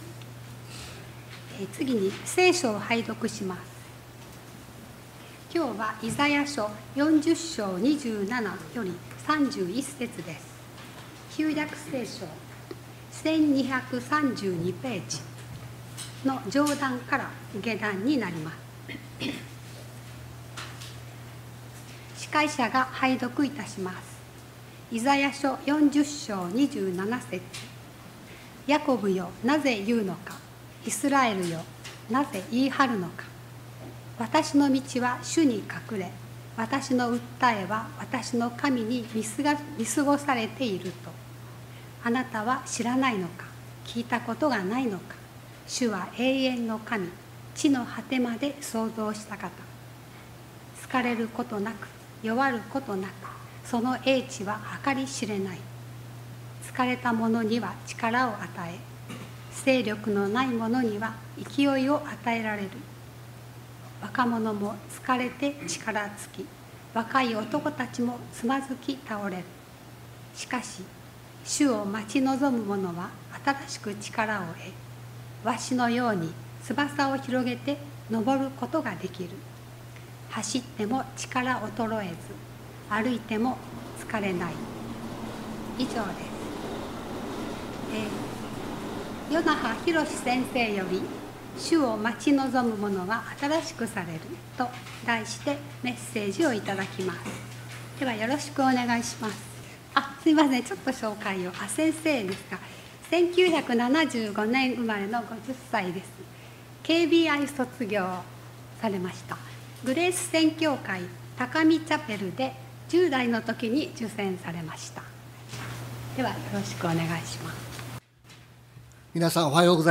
礼拝メッセージ「主を待ち望む者は新しくされる」│日本イエス・キリスト教団 柏 原 教 会